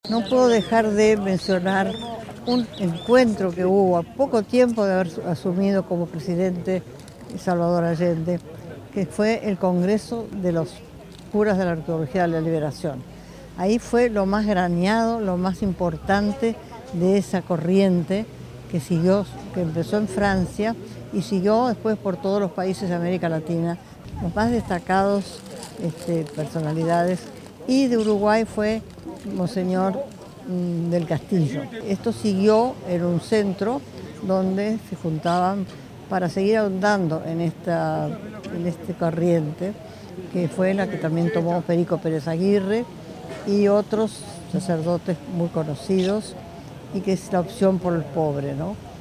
En Barros Blancos se realizó acto conmemorativo a 50 años del Golpe de Estado en Chile